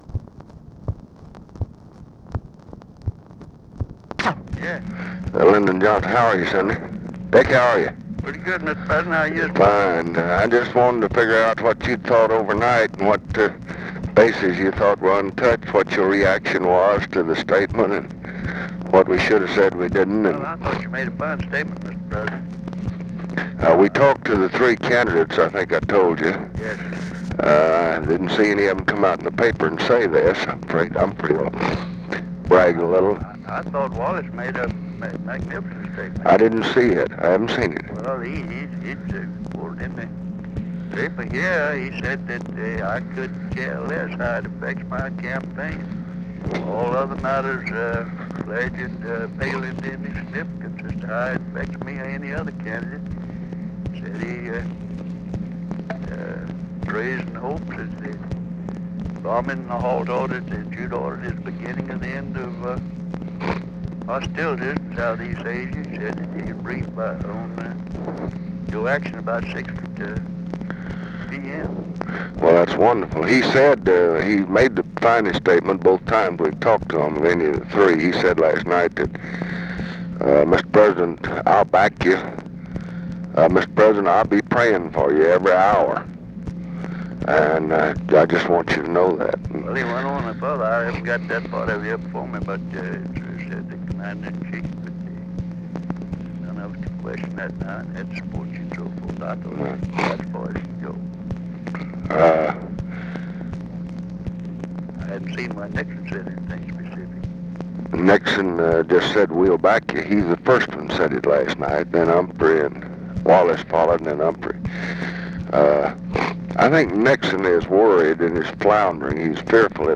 Conversation with RICHARD RUSSELL, November 1, 1968
Secret White House Tapes